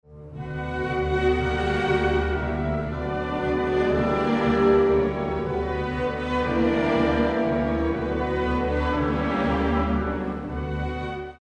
Trio
(bt. 55-94)   tema in si minore bt. 56-60 flauti e archi 12 esempio 12 Midi (formato WMV) esempio 12 di partitura (formato PDF)
esempio 12 orchestrale (formato MP3) esempio multimediale (formato formato flash)